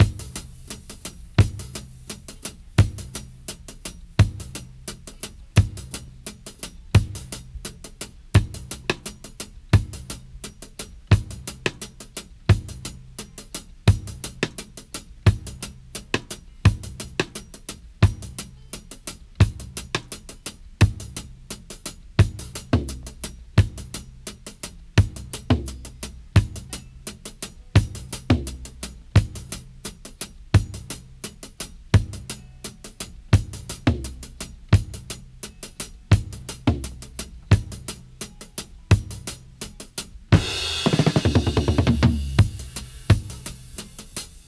85-DRY-02.wav